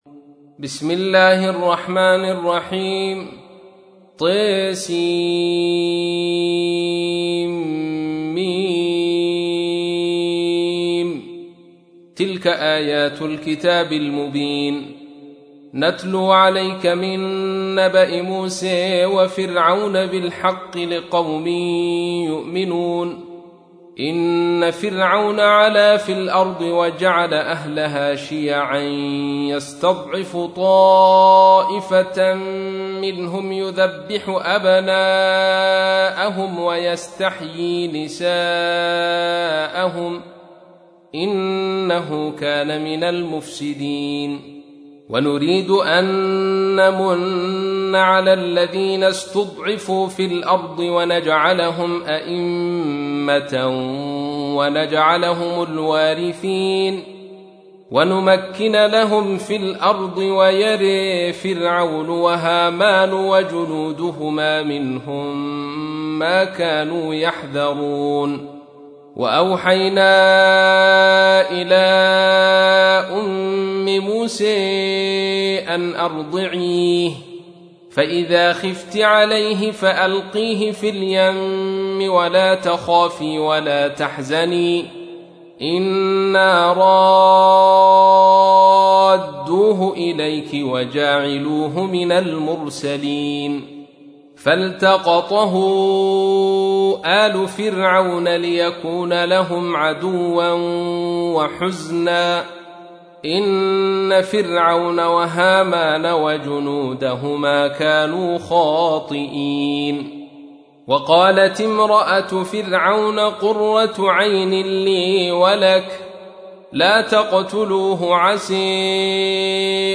تحميل : 28. سورة القصص / القارئ عبد الرشيد صوفي / القرآن الكريم / موقع يا حسين